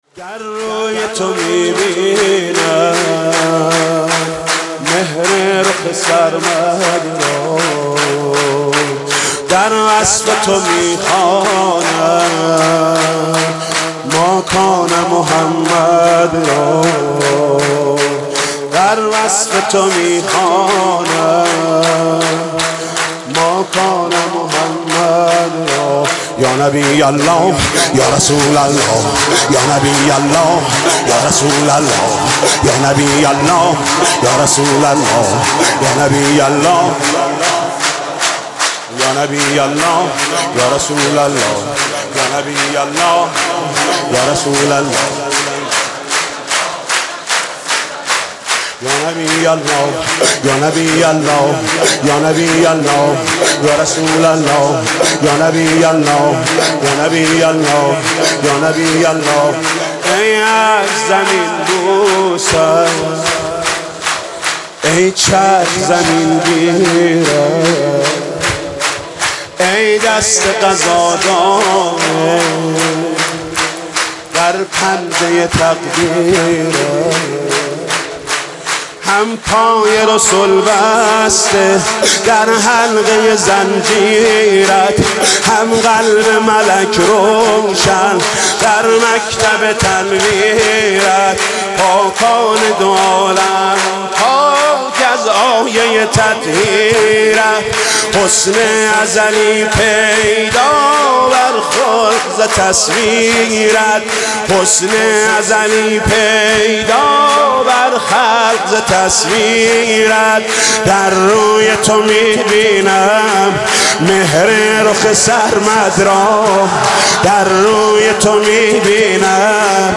میلاد حضرت محمد (سرود)